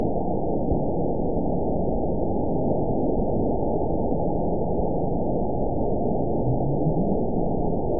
event 920344 date 03/17/24 time 21:02:14 GMT (1 year, 1 month ago) score 9.44 location TSS-AB04 detected by nrw target species NRW annotations +NRW Spectrogram: Frequency (kHz) vs. Time (s) audio not available .wav